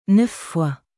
neuf foisヌァフ フォワ